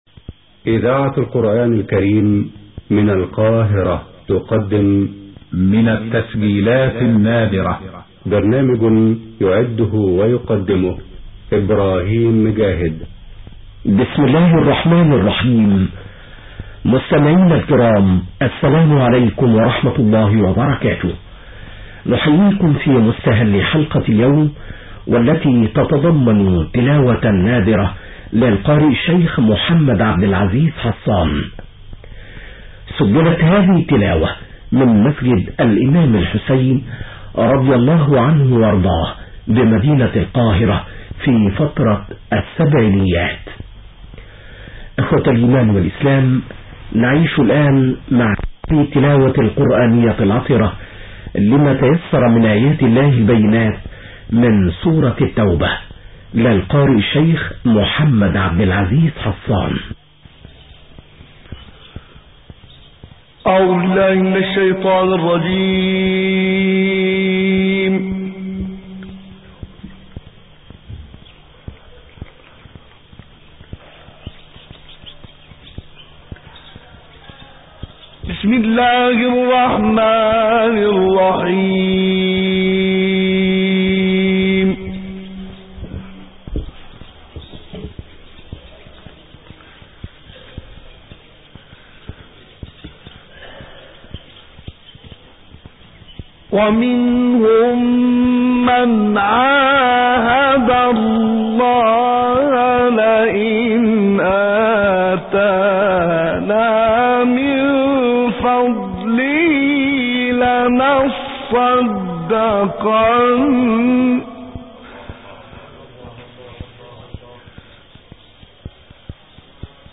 گروه فعالیت‌های قرآنی: تلاوتی دلنشین از سوره توبه با صدای محمد عبدالعزیز حصان که در دهه هفتاد میلادی اجرا شده است را می‌شنویم.